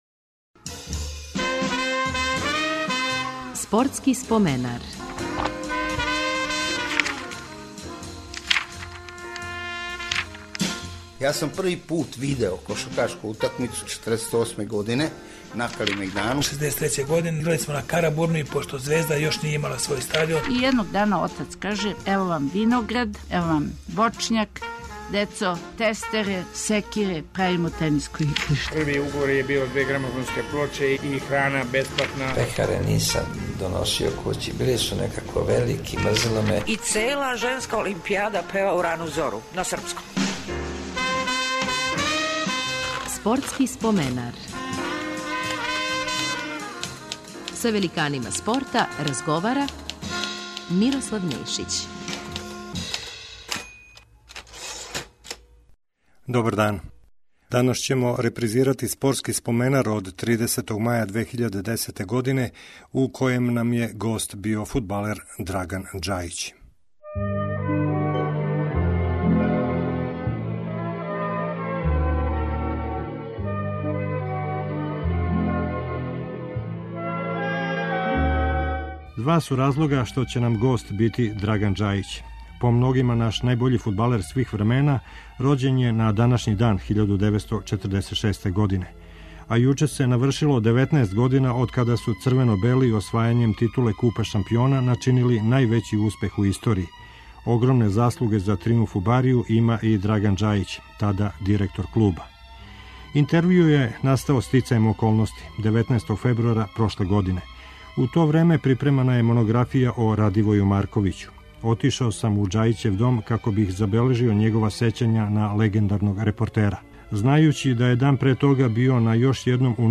Репризираћемо емисију у којој је гост био Драган Џајић, према многобројним оценама, најбољи српски фудбалер свих времена.